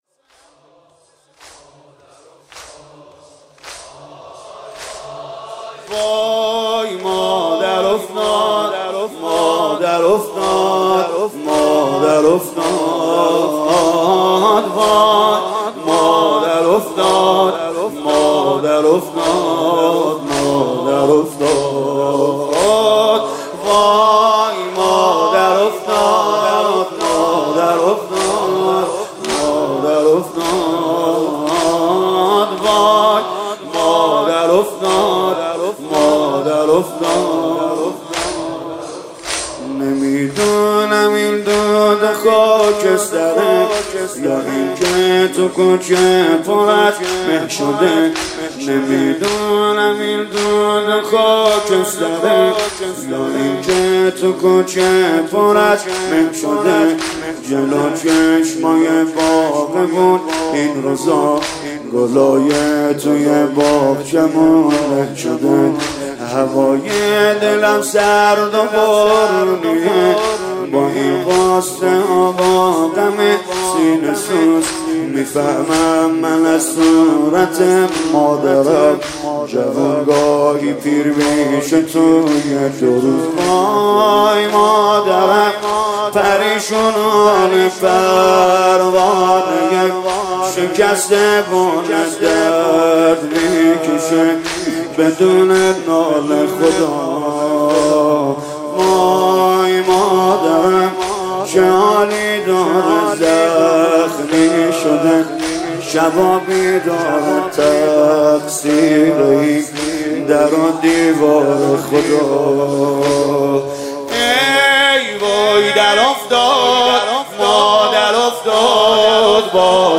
خیمه گاه - عاشقان اهل بیت - زمینه- وای مادر افتاد- سید رضا نریمانی